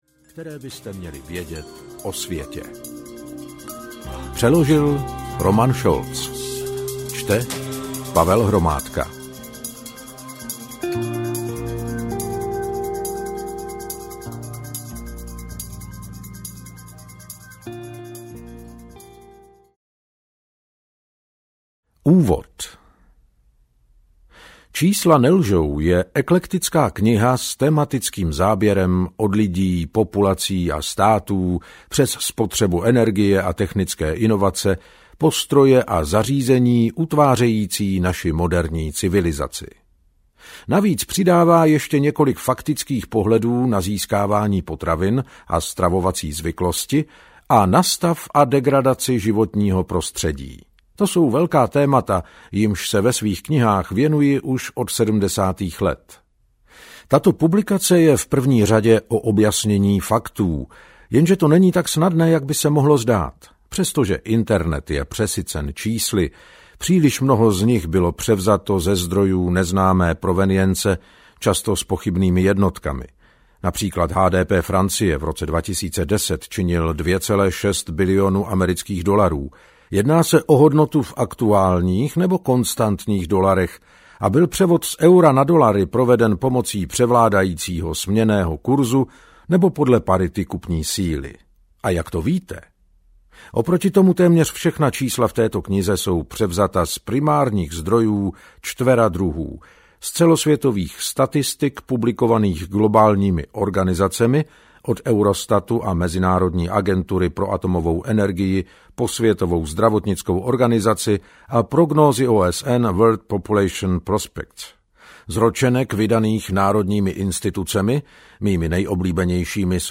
Čísla nelžou audiokniha
Ukázka z knihy